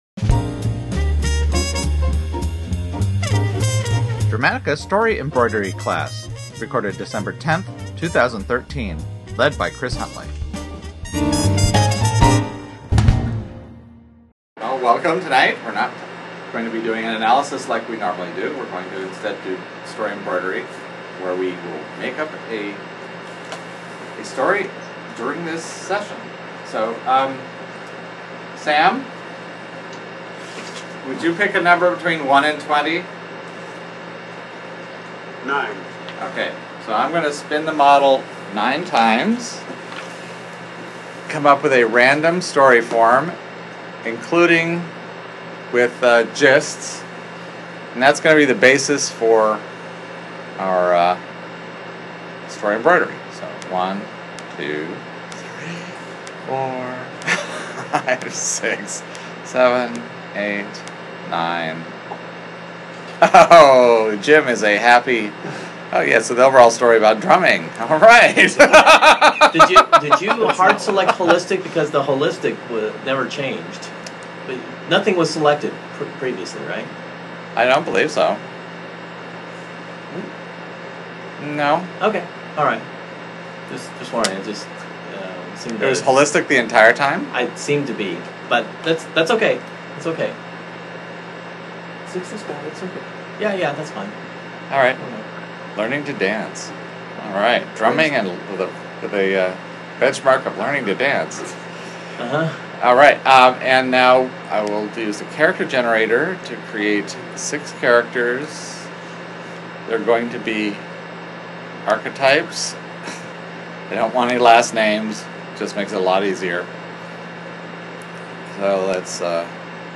Delightful recordings of Dramatica Users attempting to better understand what makes great stories so great. Each podcast focuses on a popular or critically-acclaimed film. By breaking down story into fine detail, the group gains a better appreciation of the theory and how better to apply the concepts into their own work.